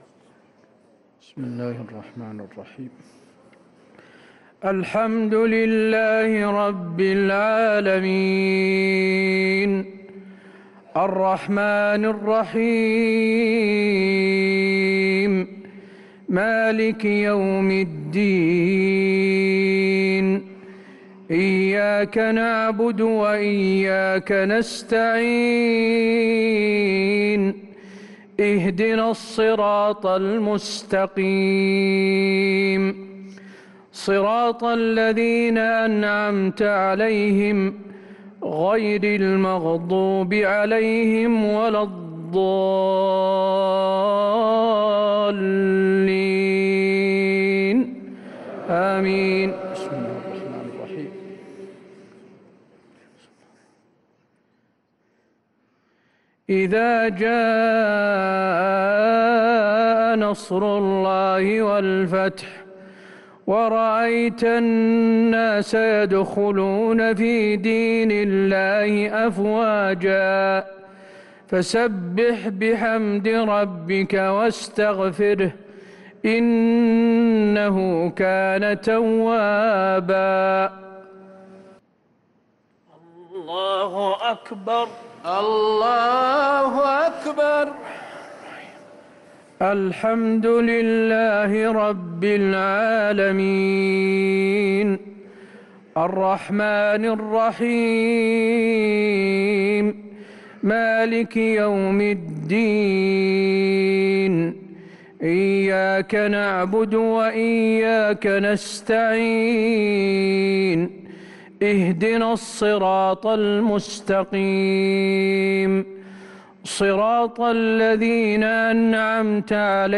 صلاة المغرب للقارئ حسين آل الشيخ 18 جمادي الأول 1444 هـ